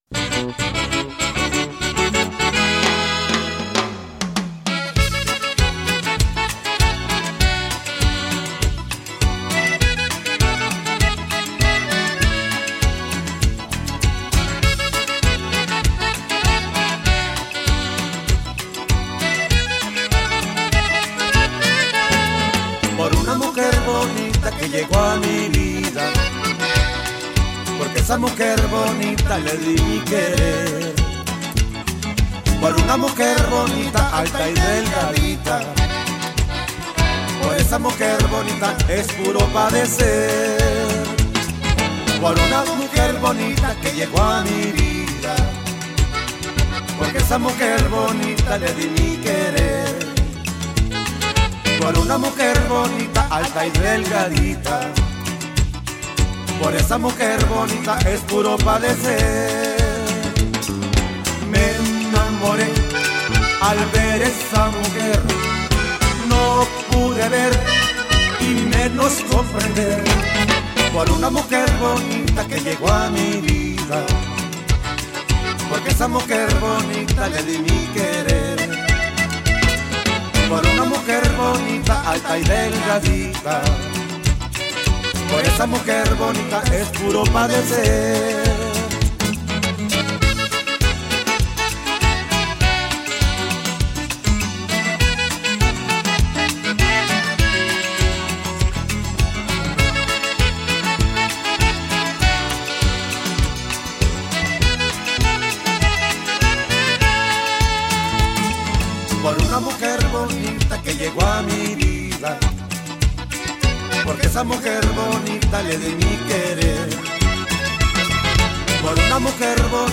Tejano squeezebox legend
bouncy bit of border pop